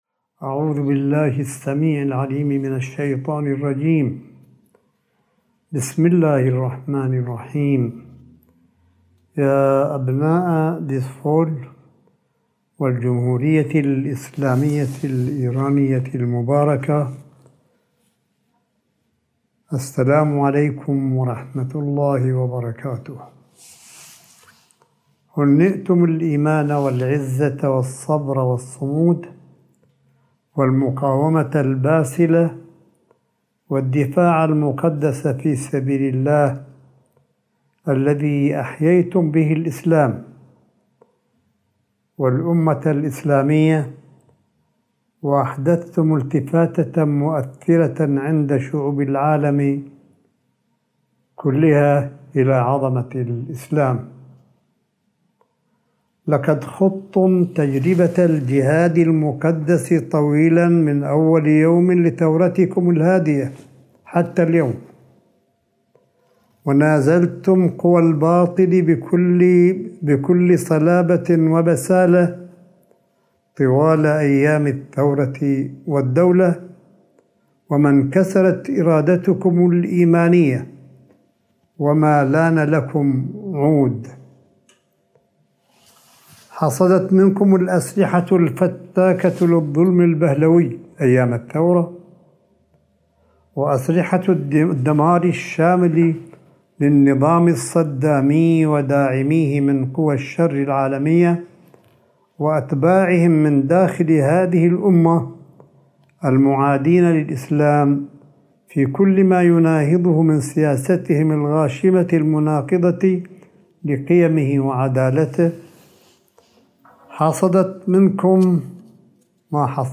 ملف صوتي لكلمة سماحة آية الله الشيخ عيسى أحمد قاسم الموجّهة لأهالي دزفول جنوب الجمهورية الإسلامية بذكرى يوم المقاومة والصمود إبان حرب الدفاع المقدس، وذلك اليوم الثلاثاء ٢٥ مايو ٢٠٢١م.